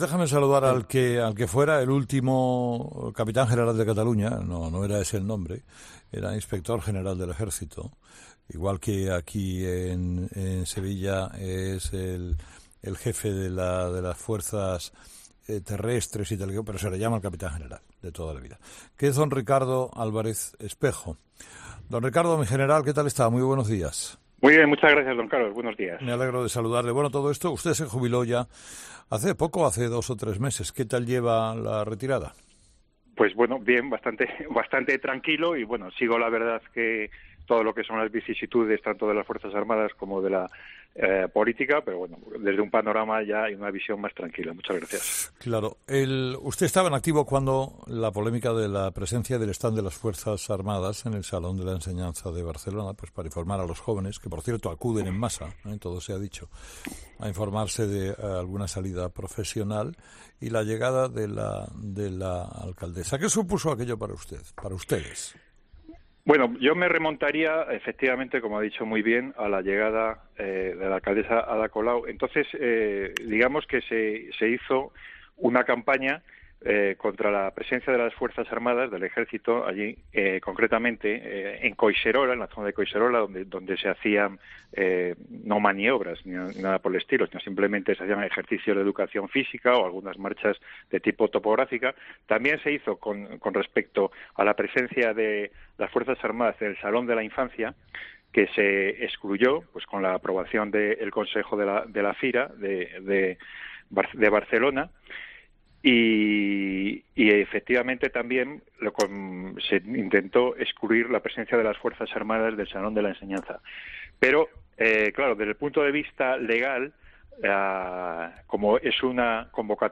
El teniente general del Ejército de Tierra, Ricardo Álvarez Espejo, ha defendido en 'Herrera en COPE' la presencia de las Fuerzas Armadas en la Feria del Salón de la Enseñanza, celebrada en Barcelona, después de que los Mossos hayan tenido que proteger su stand por las protestas de manifestantes.